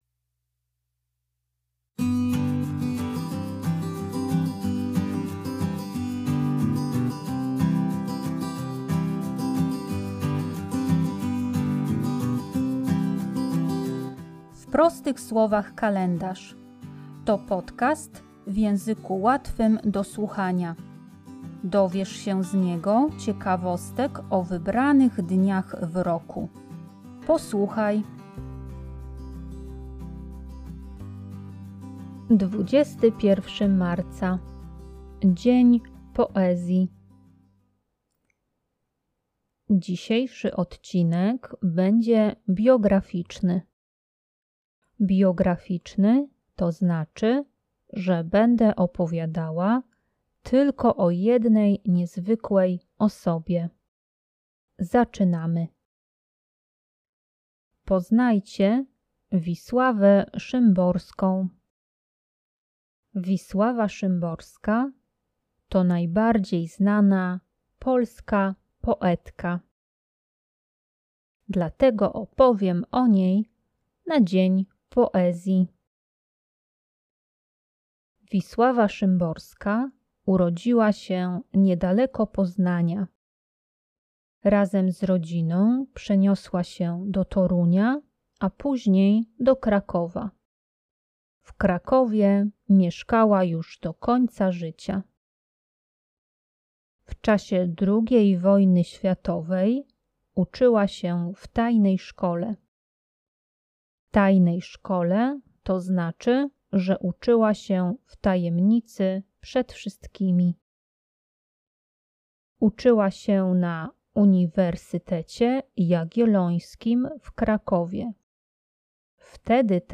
Tekst i lektorka